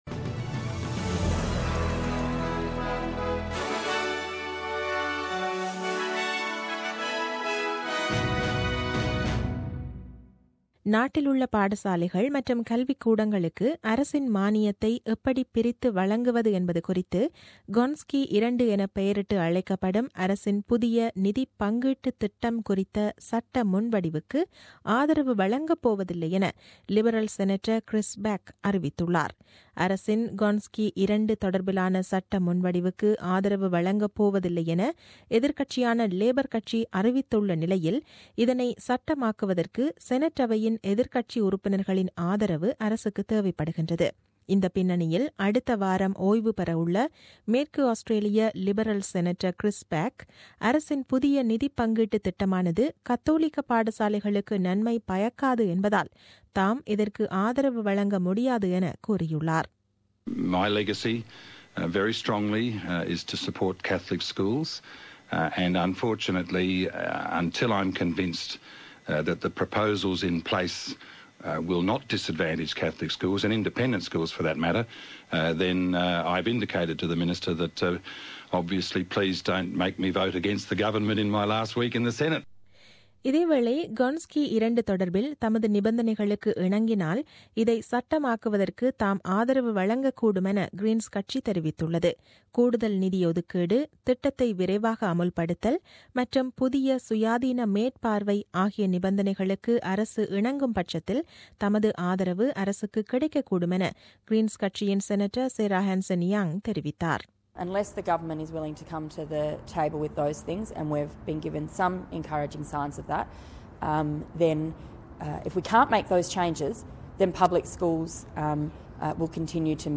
The news bulletin aired on 19 June 2017 at 8pm.